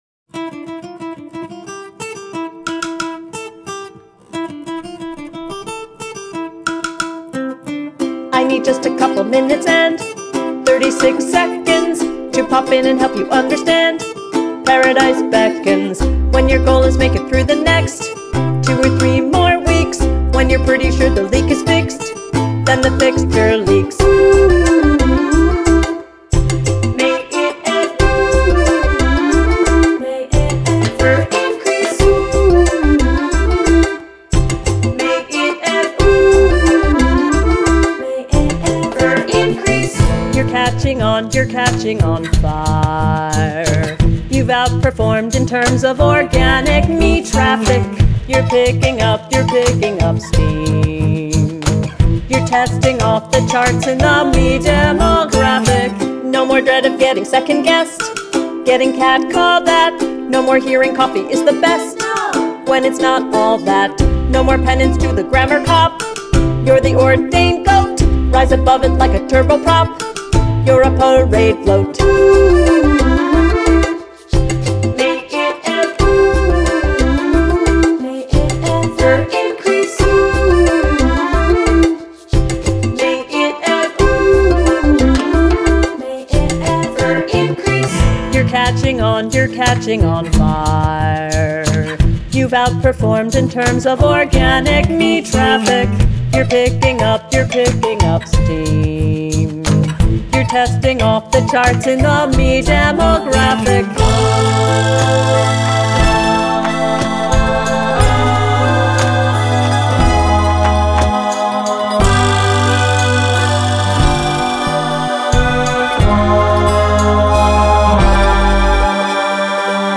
lead vocals
additional vocals